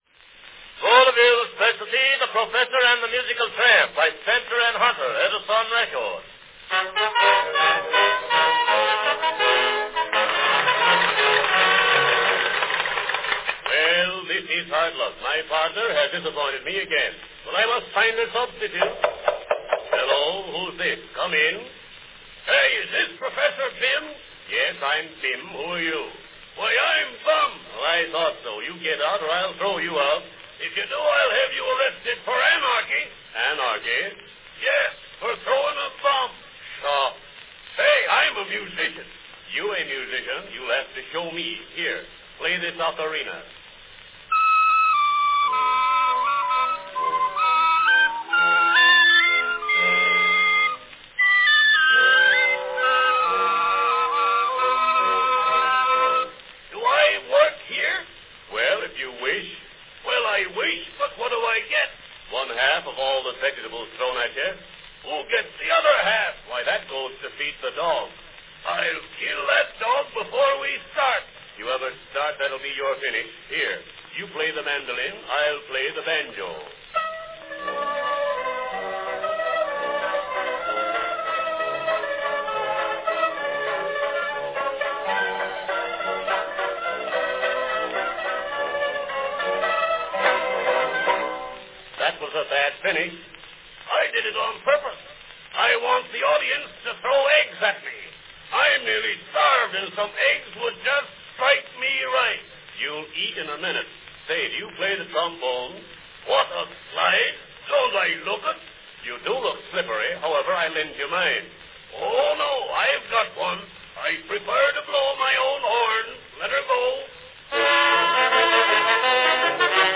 Category Vaudeville sketch
The orchestra plays the opening to the act.